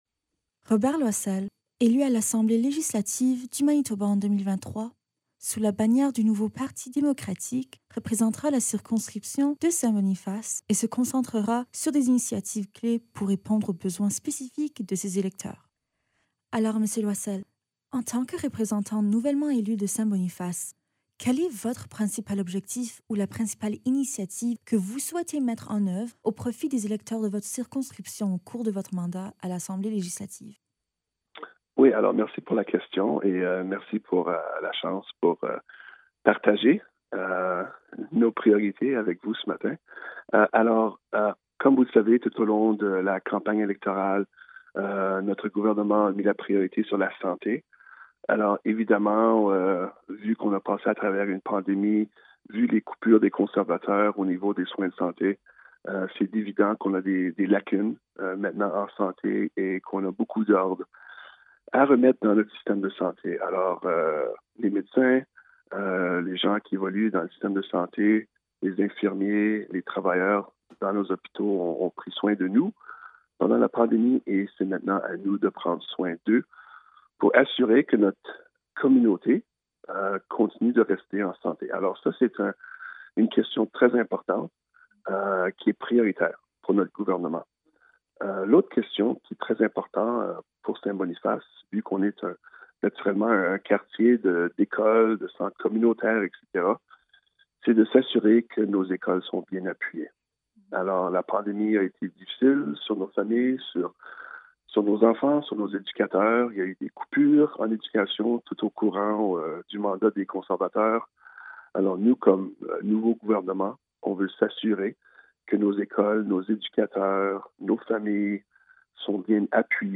Le reportage